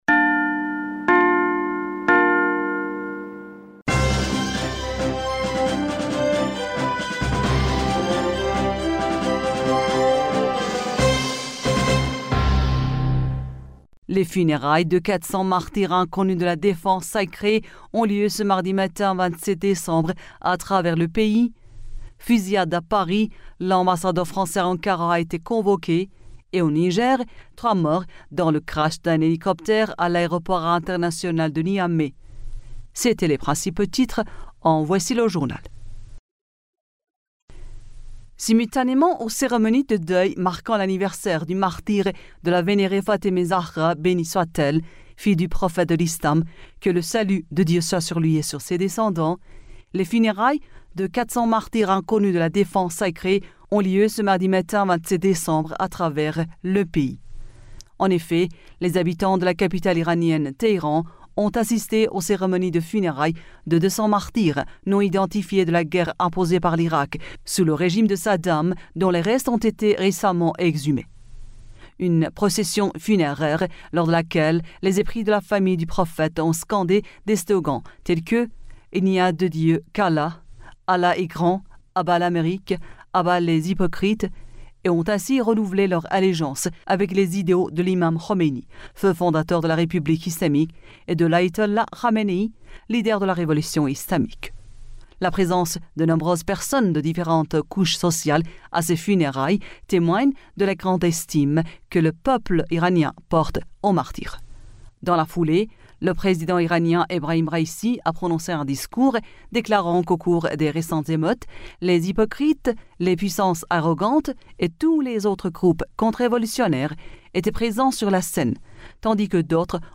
Bulletin d'information du 27 Décembre